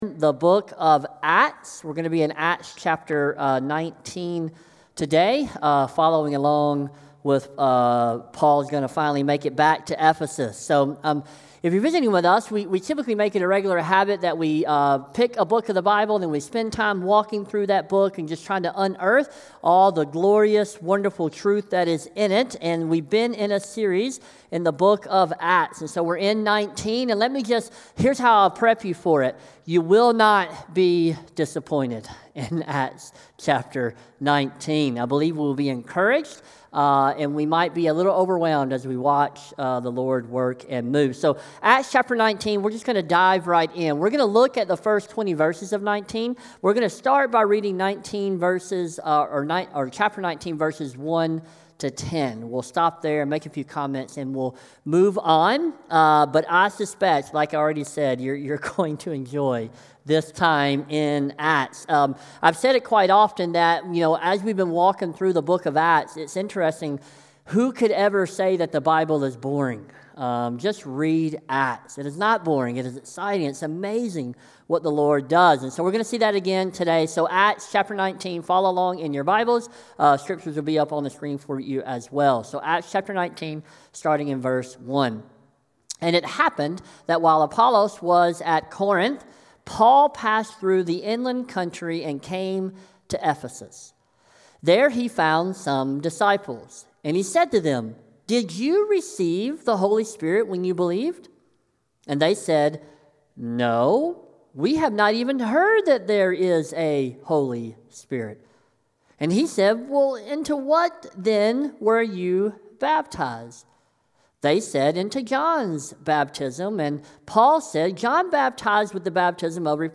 SERMON | Acts 19: 1-20 | Sons of Sceva | Light in the Desert Church